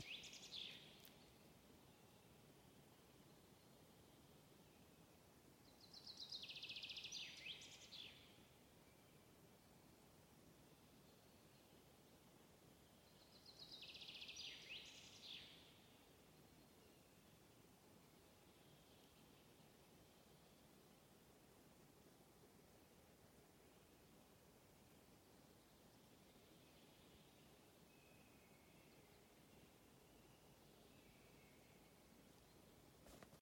Žubīte, Fringilla coelebs
StatussDzied ligzdošanai piemērotā biotopā (D)